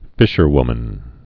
(fĭshər-wmən)